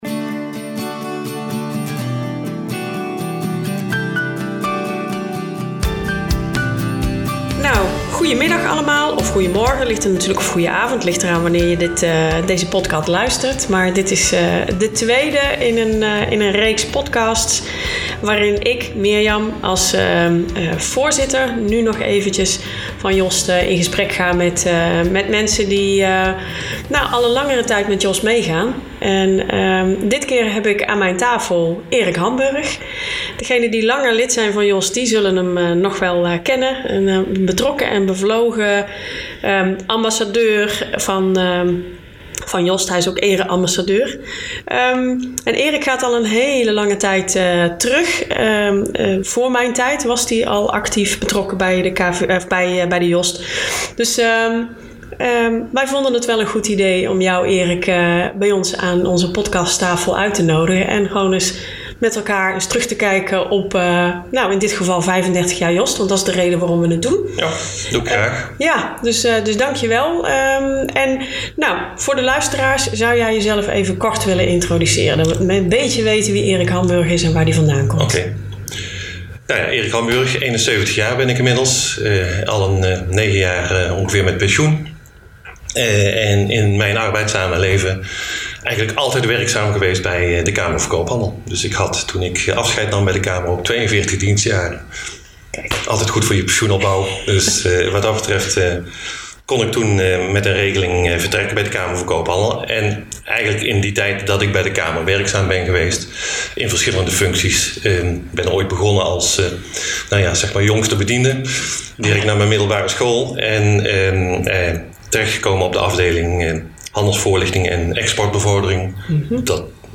Dit gesprek kun je hier als podcast beluisteren.